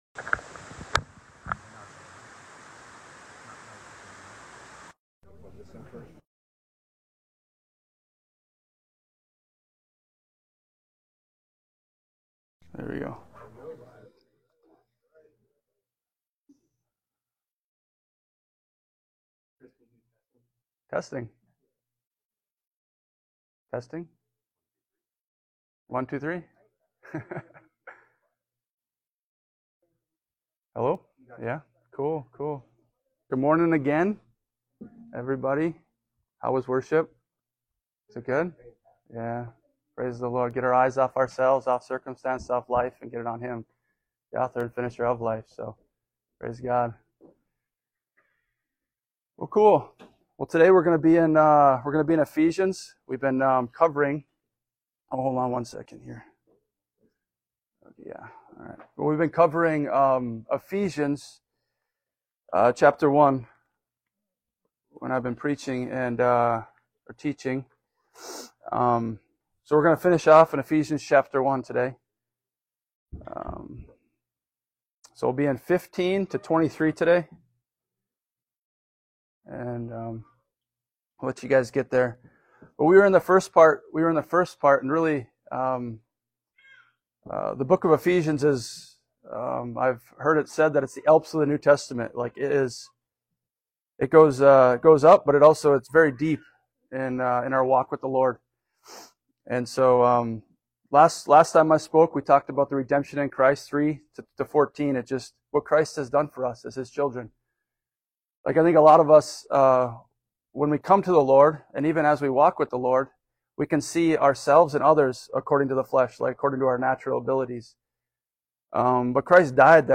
Guest Speaker Service Type: Sunday Morning « “Are You Thirsty?”